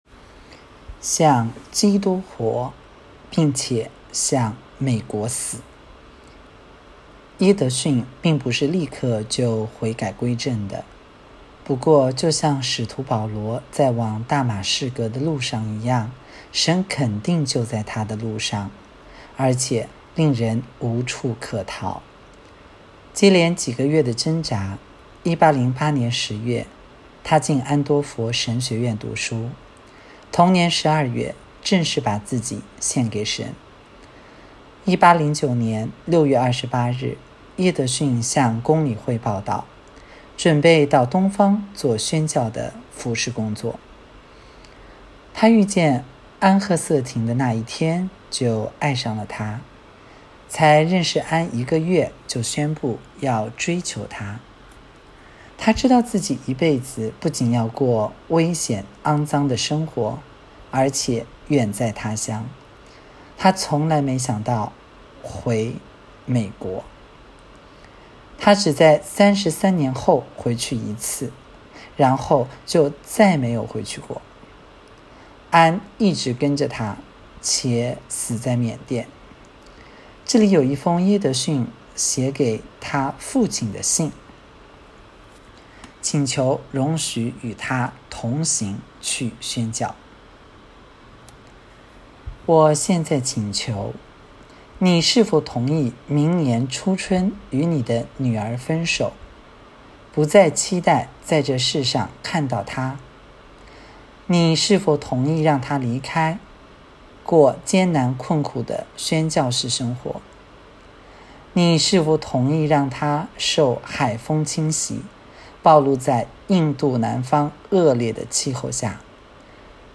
2024年8月23日 “伴你读书”，正在为您朗读：《活出热情》 欢迎点击下方音频聆听朗读内容 音频 https